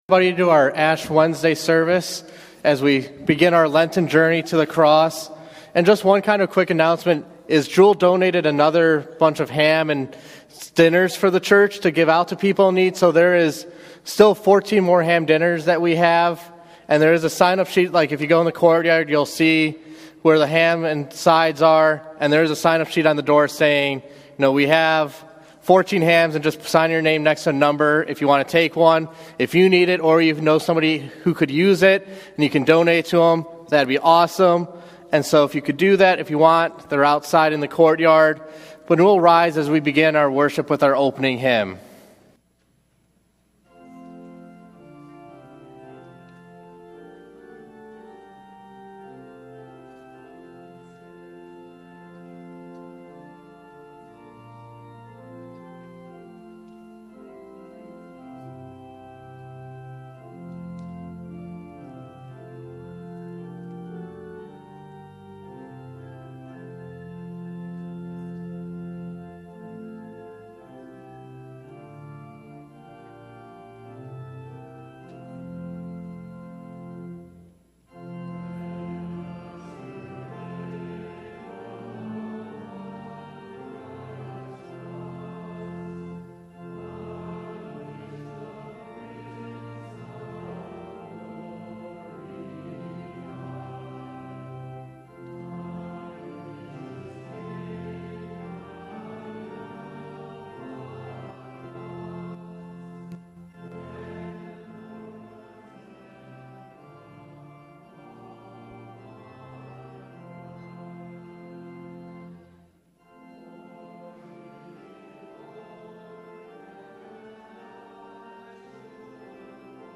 Feb 14 / Wed Eve – Lenten Worship Service audio